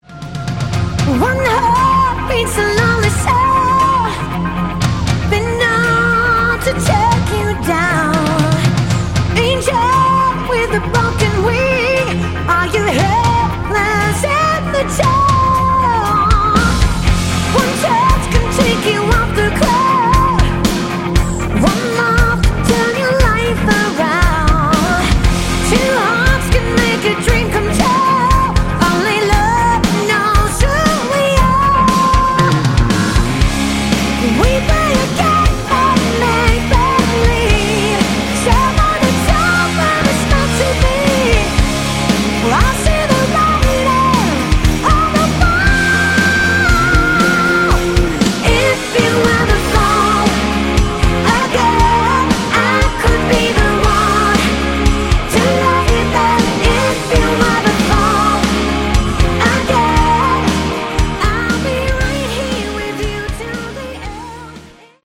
Category: Hard Rock
keyboards
guitars
bass
drums